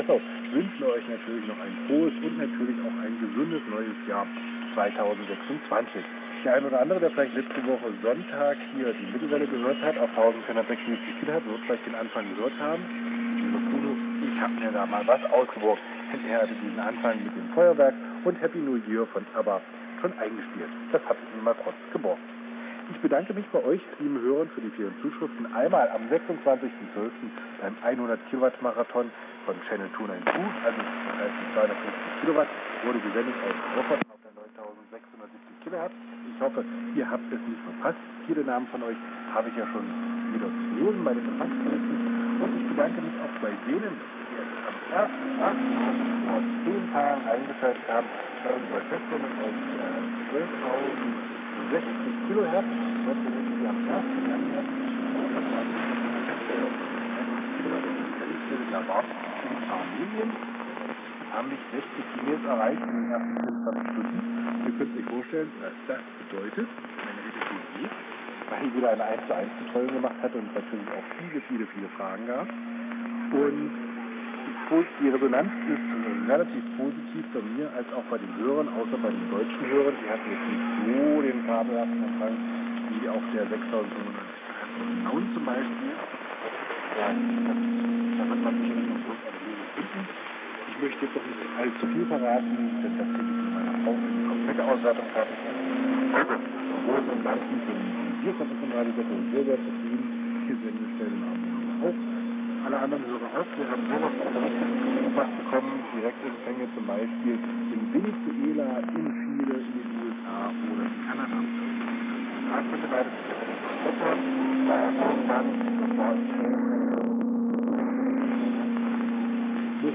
Empfangsort: Monschau/an der belg. Grenze
Der Sender steht in Östereich 1476 khz Mittelwellensender
Mitschnitt einer ( Ausszug)  Sendung vom 11 Jan 2026 22 Uhr (Küchenzeit):